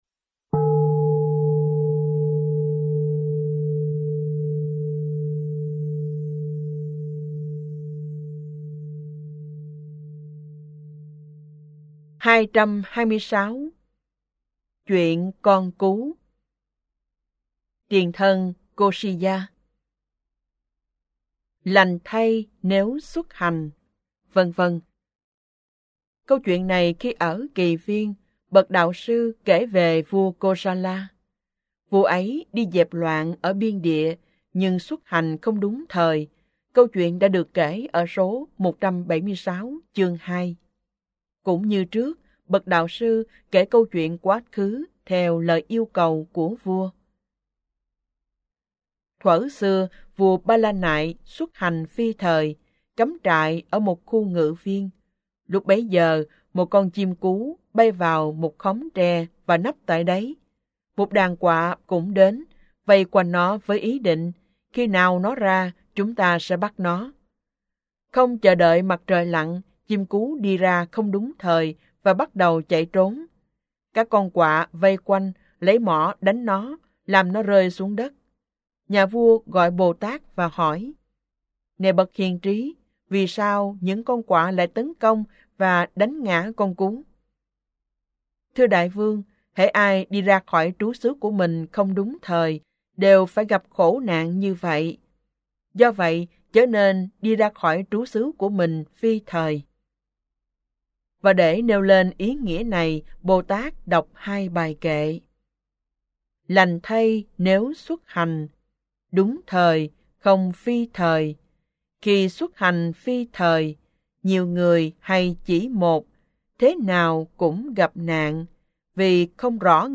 Kinh Tieu Bo 3 - Giong Mien Nam